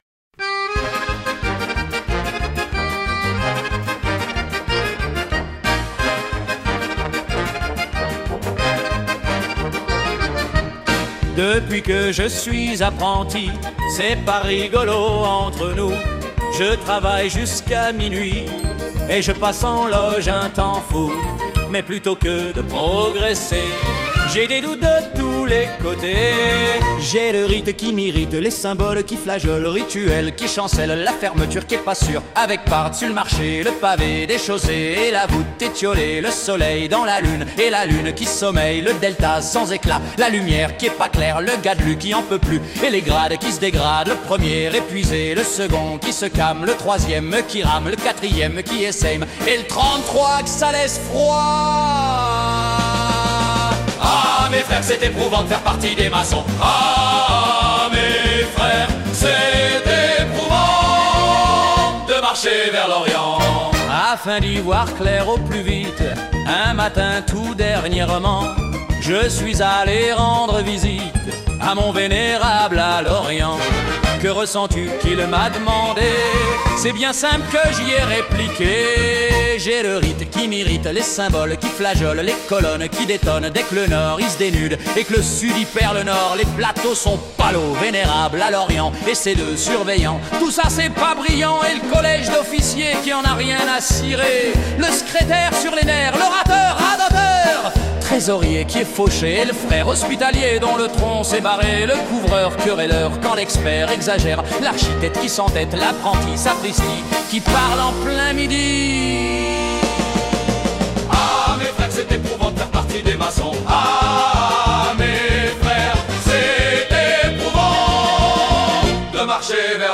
chanson de comique troupier